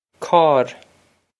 Cawr
This is an approximate phonetic pronunciation of the phrase.